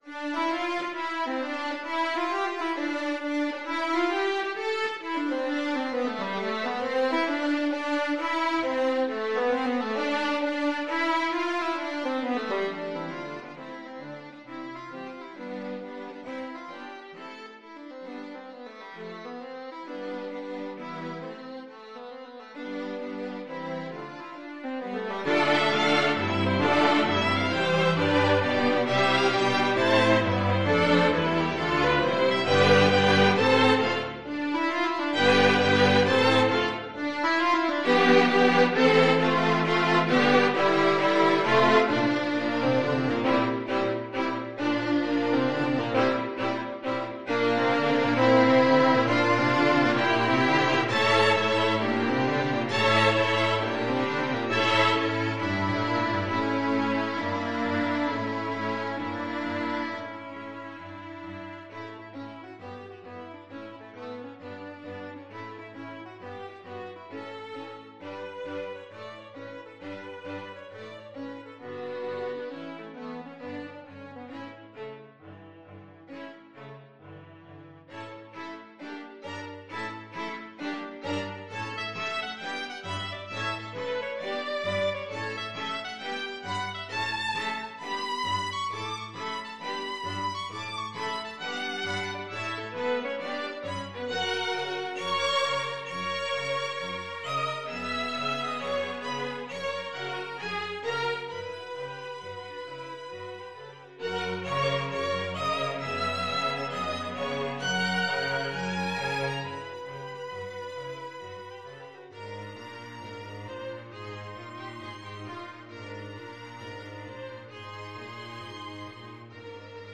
Violin 1Violin 2ViolaCello
6/8 (View more 6/8 Music)
Allegro. = 100 Vivace (View more music marked Allegro)
Classical (View more Classical String Quartet Music)